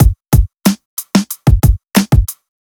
FK092BEAT2-L.wav